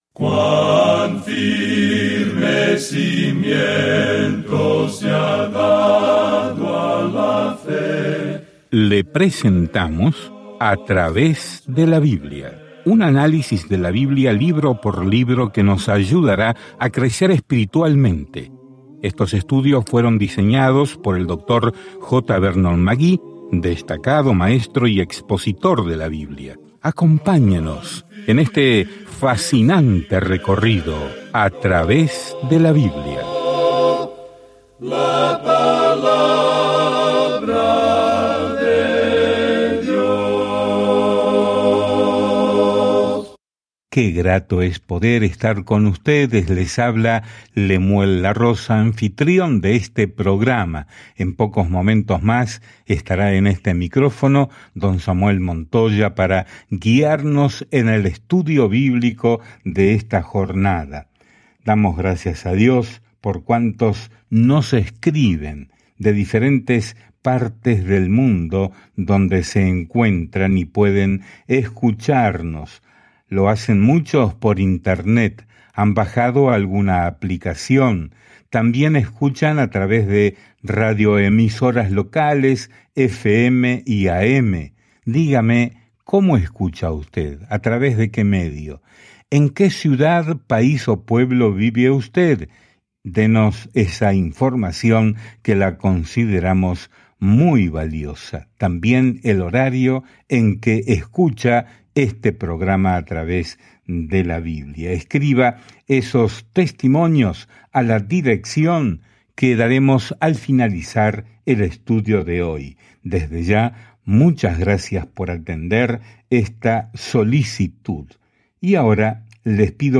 Escrituras JOEL 1:14-20 Día 2 Comenzar este Plan Día 4 Acerca de este Plan Dios envía una plaga de langostas para juzgar a Israel, pero detrás de su juicio hay una descripción de un futuro “día del Señor” profético cuando Dios finalmente dé su opinión. Viaja diariamente a través de Joel mientras escuchas el estudio de audio y lees versículos seleccionados de la palabra de Dios.